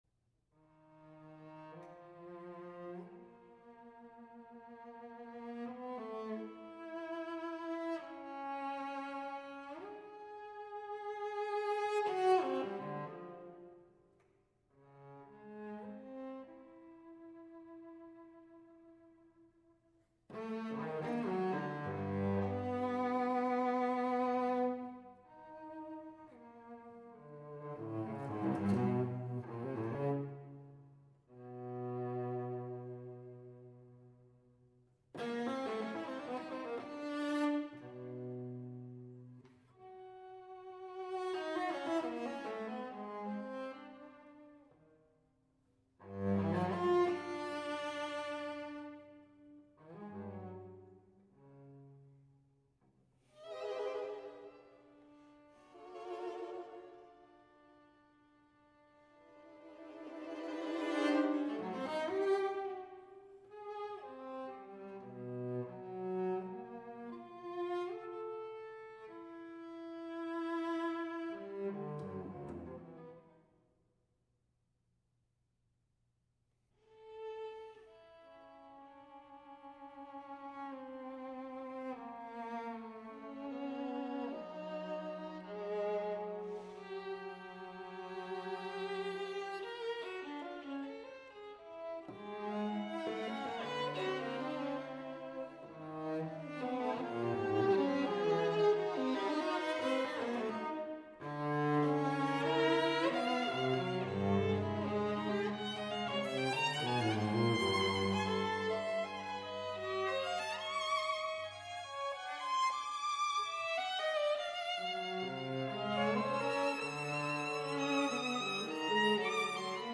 POCO ADAGIO (403)